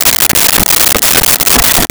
Saw Wood 01
Saw Wood 01.wav